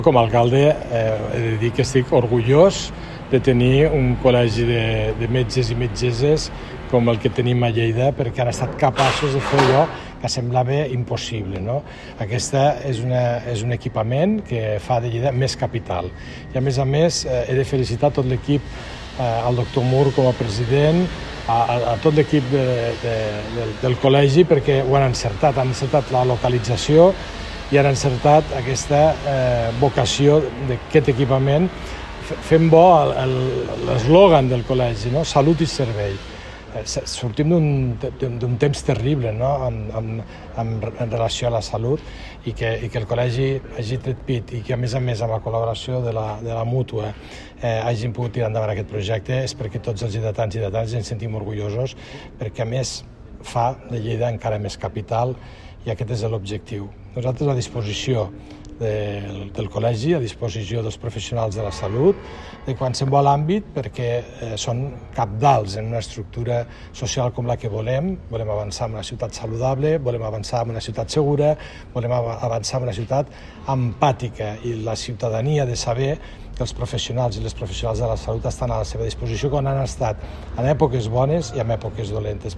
Tall de veu del paer en cap, Fèlix Larrosa